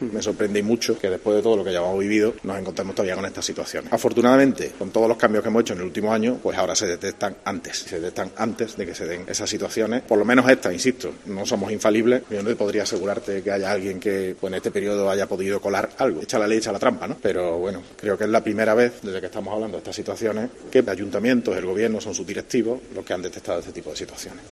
En una atención a medios, el regidor ha aclarado que "a raíz de la situación que se sigue viviendo" en dicha área, "se establecieron muchos más controles, en el sentido de que los contratos menores en Córdoba pasan el mismo trámite que el contrato de mayor cuantía que se pueda imaginar, que al final es publicarlo obligatoriamente en la Plataforma de Contratación del Estado".